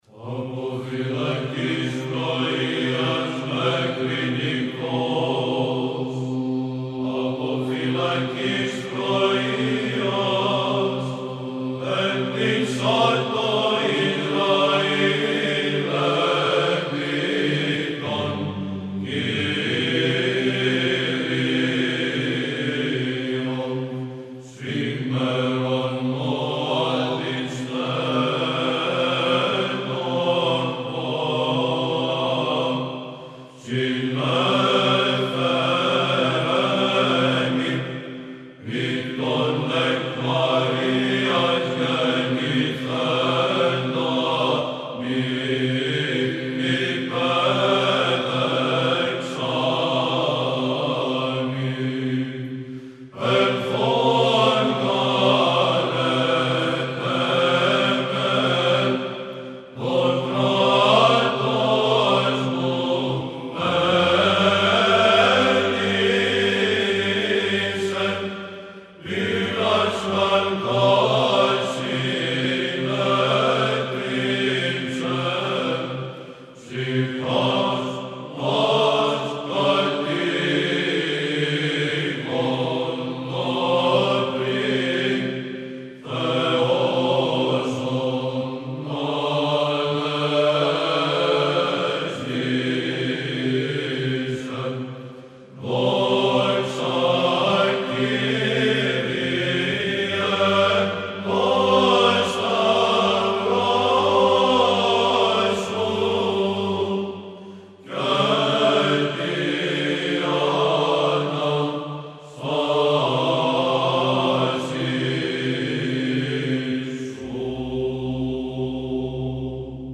Ακούστε το από την Βυζαντινή χορωδία του Συλλόγου Μουσικοφίλων Κωνσταντινουπολιτών.